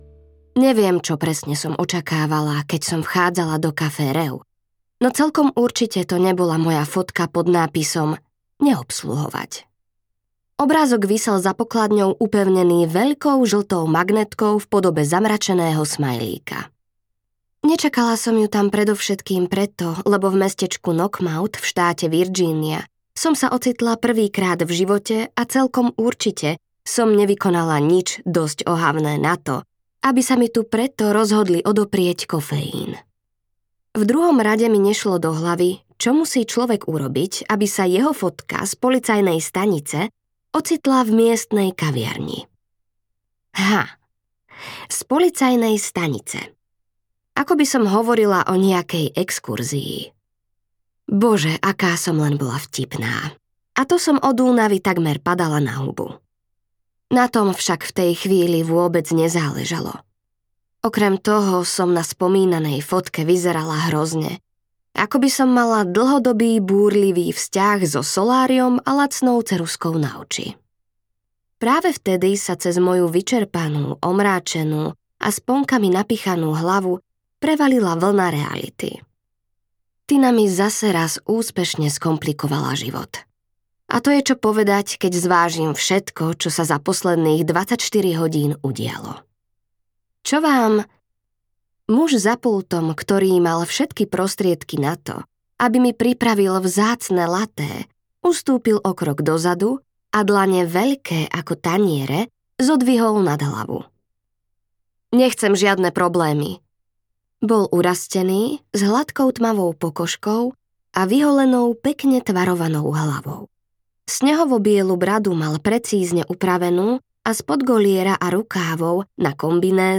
Veci, ktoré v nás ostanú navždy audiokniha
Ukázka z knihy